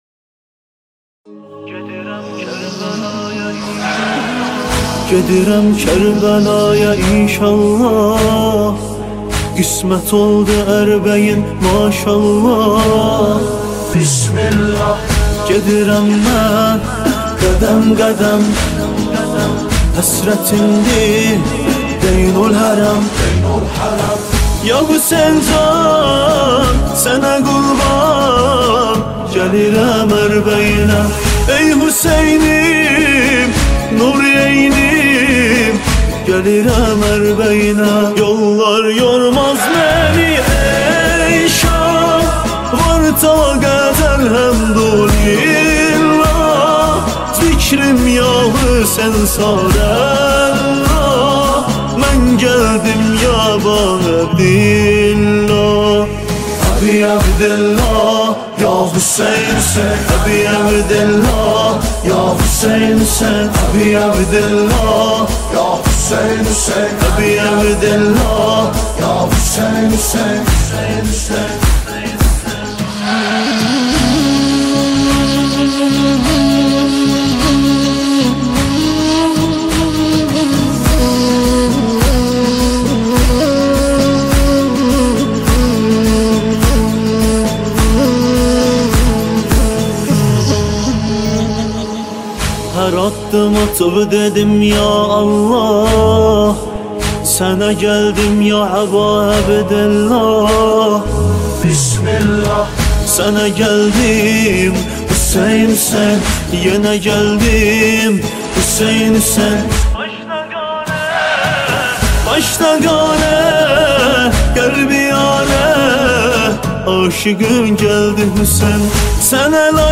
نماهنگ آذری
مداحی ترکی